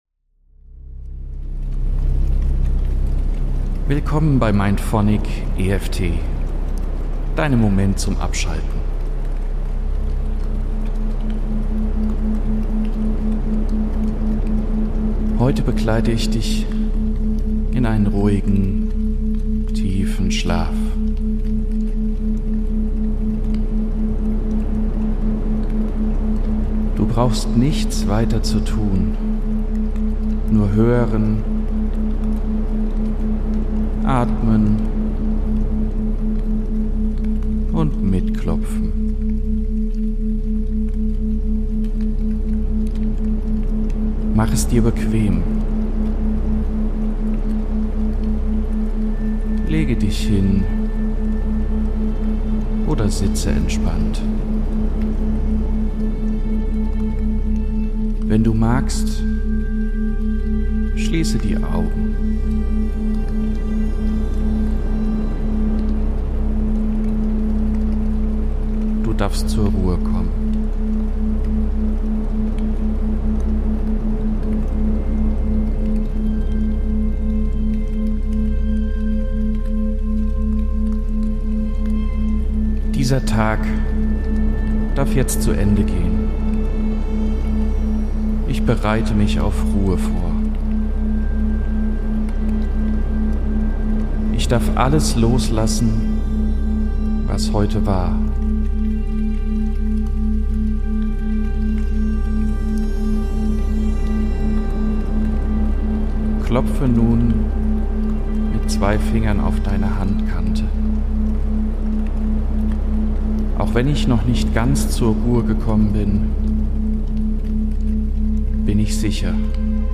In dieser geführten MindPhonic-EFT-Session begleite ich dich in einen entspannten Zustand, der dir beim Einschlafen helfen kann. Wir kombinieren sanftes Klopfen auf Akupressurpunkte mit beruhigenden Frequenzen im Theta- und Delta-Bereich – ganz ohne Druck, dafür mit viel Raum für Loslassen, Ruhe und Geborgenheit.